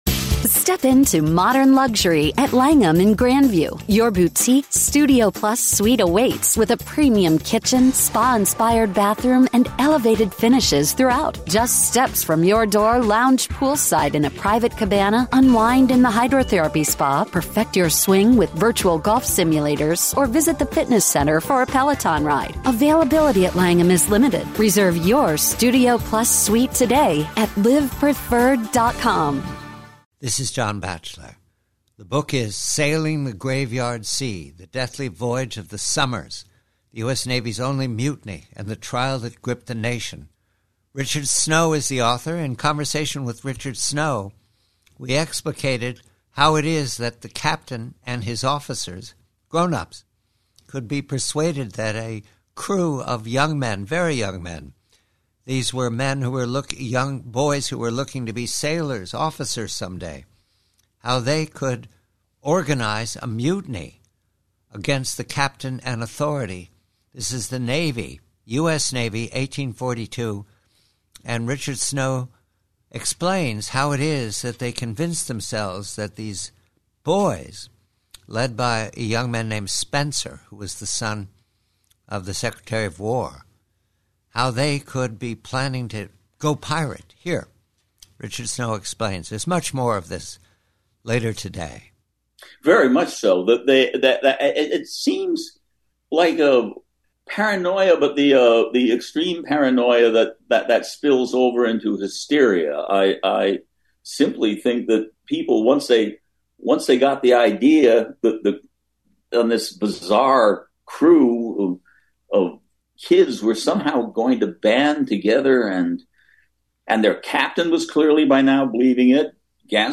a long conversation